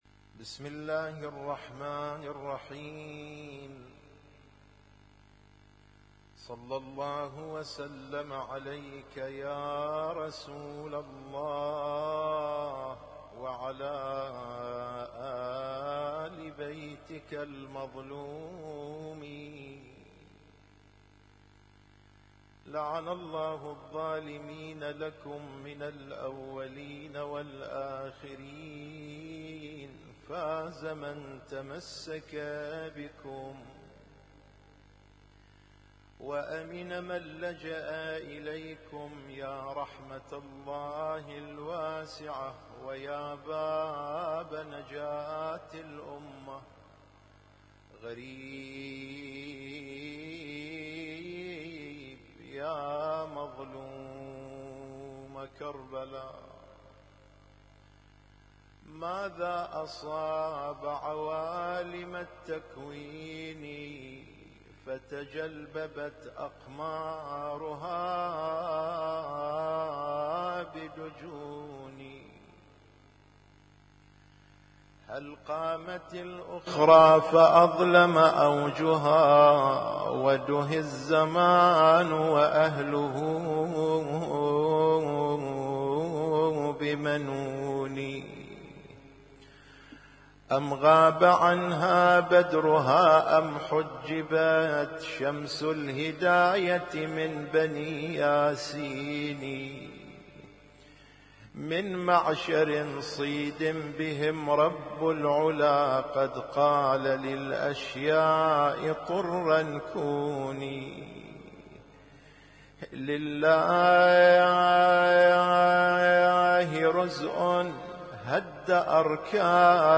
Husainyt Alnoor Rumaithiya Kuwait
البث المباشر